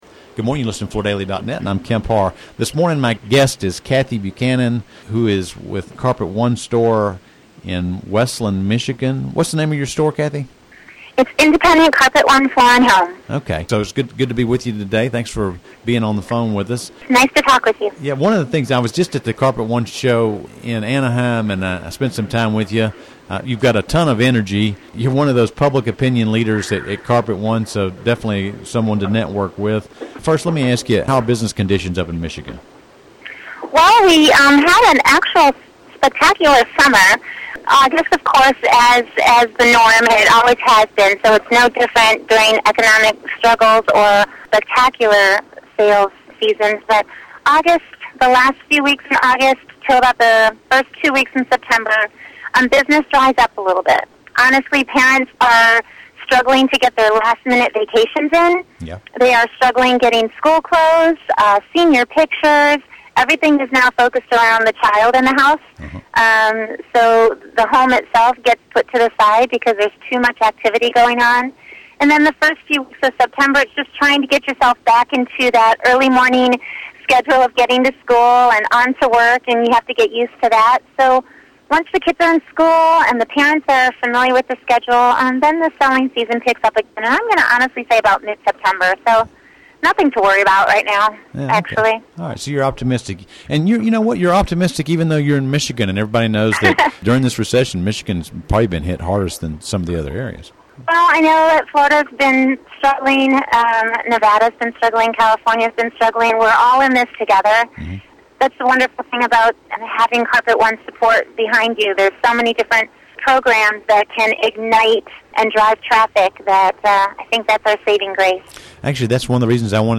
Healthy Living was rolled out last summer as a value added differentiation program to help separate the Carpet One installation experience from other retailers and big box stores. Listen to the interview